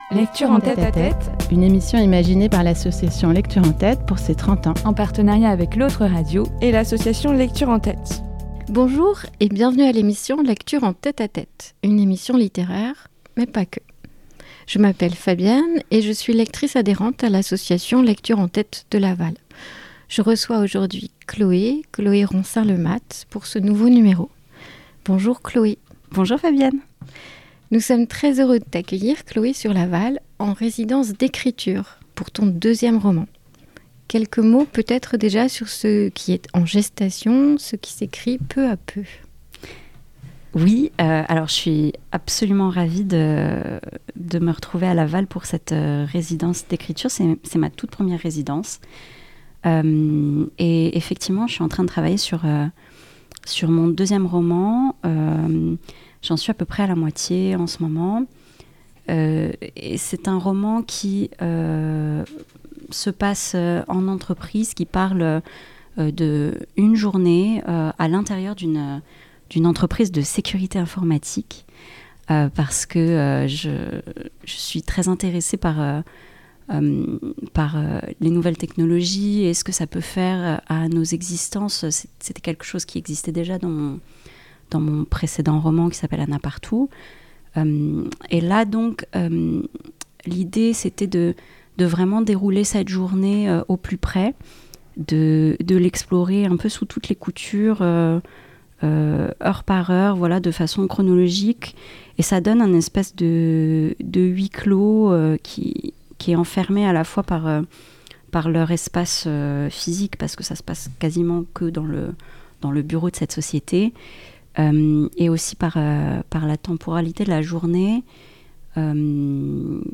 Lectures : extraits du roman "Anna Partout"